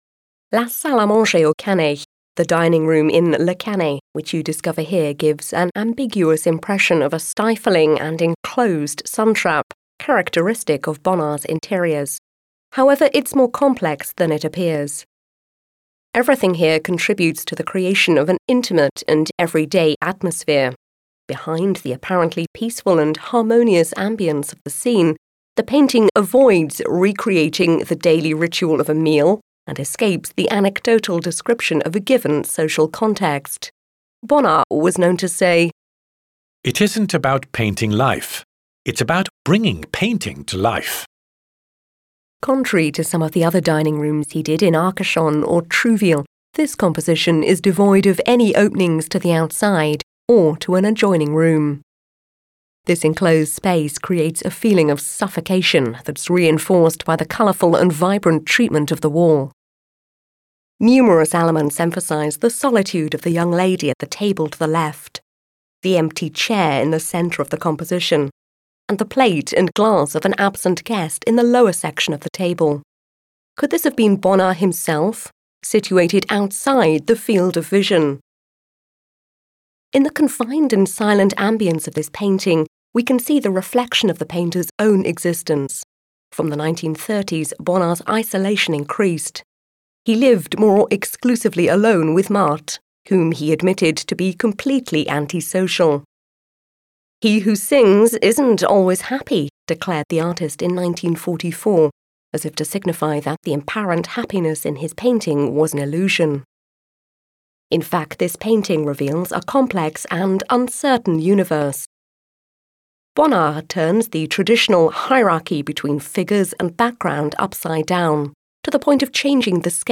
Les audioguides de la Collection